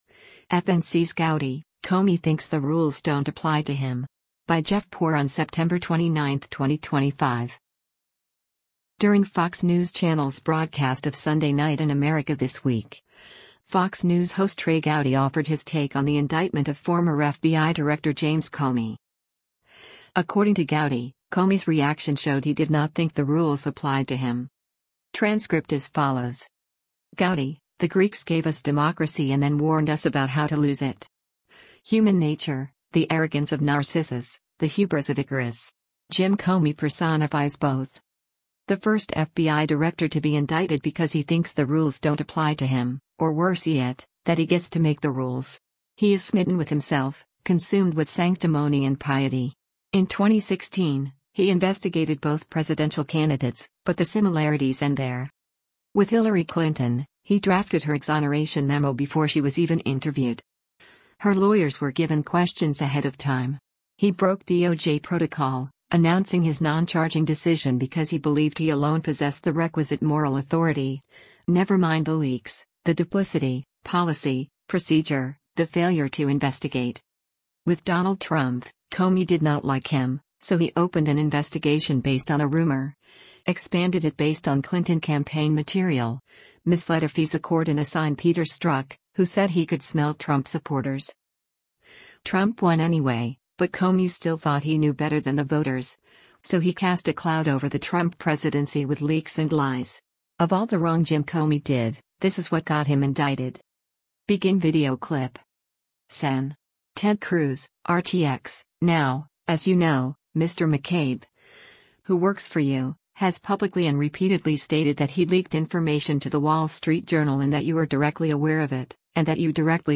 During Fox News Channel’s broadcast of “Sunday Night in America” this week, Fox News host Trey Gowdy offered his take on the indictment of former FBI Director James Comey.